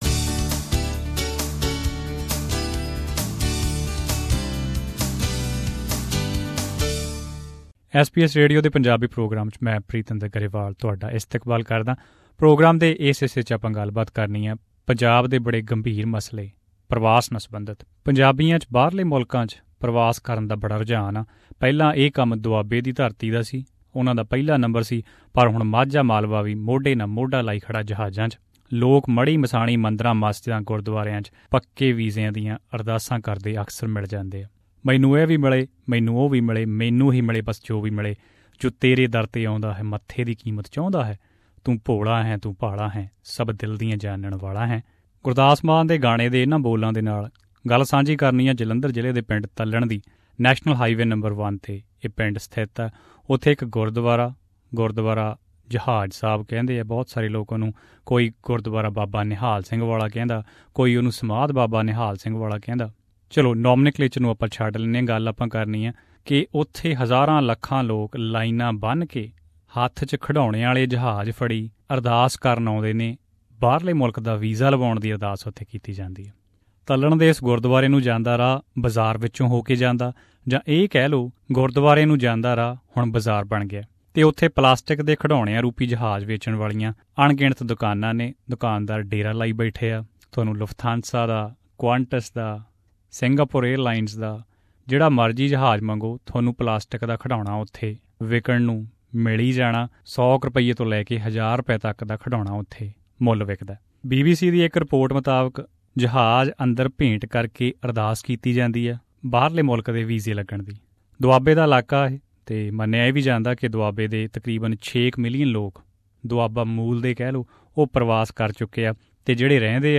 According to a BBC report, this 150-year-old Sikh Gurdwara (temple) in Talhan village in the northern Indian state of Punjab is the go-to place for thousands of Sikhs wanting to travel out of India. Listen to this audio report...